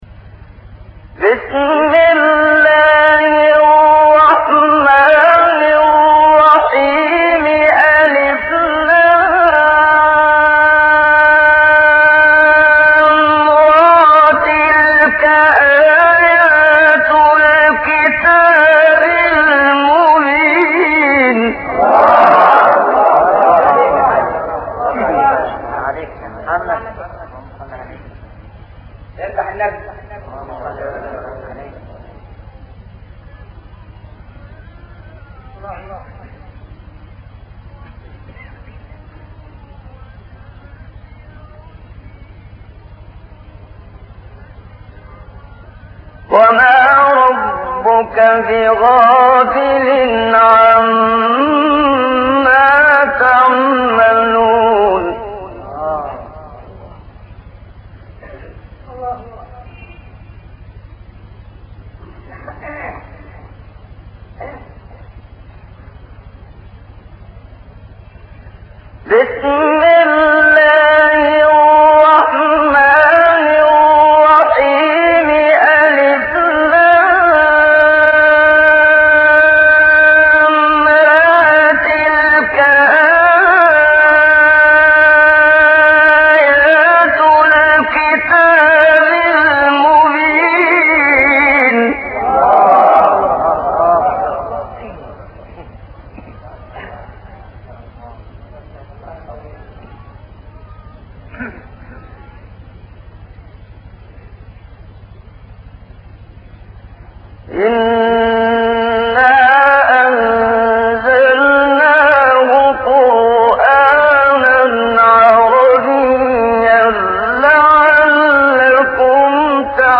آیه 123 سوره هود و آیات ابتدایی سوره یوسف منشاوی | نغمات قرآن | دانلود تلاوت قرآن